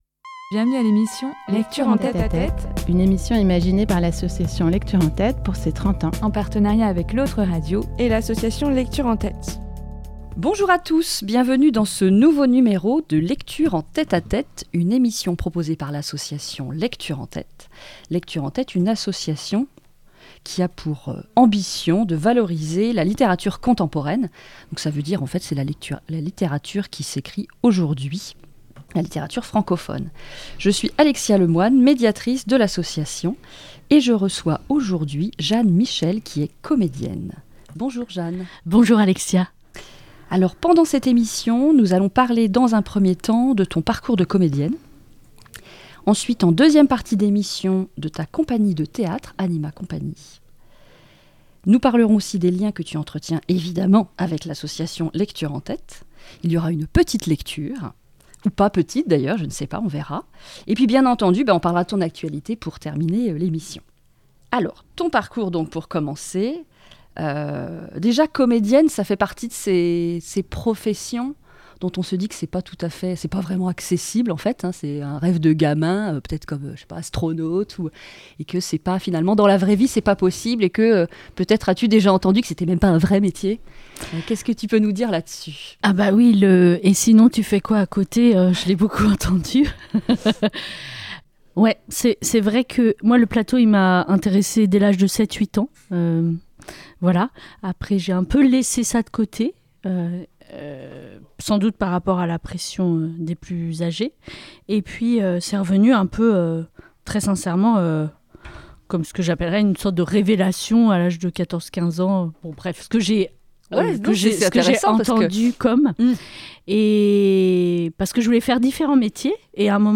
Elle terminera cet entretien avec une lecture d'un extrait d'un texte de Marie Rouzin, Treize ages de la vie d'une femme (Castor Astral, 2024)